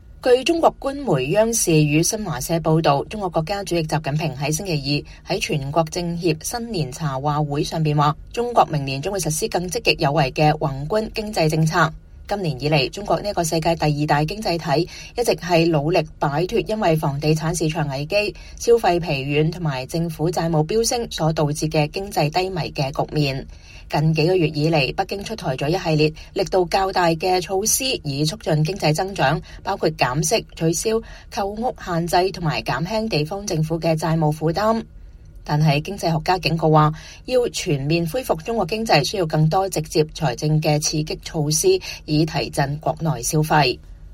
習近平在2024年終的全國政協新年茶話會上表示，中國明年將實施 “更積極有為的” 宏觀經濟政策；外界持續呼籲北京當局出台更多直接財政刺激措施以提振國內消費。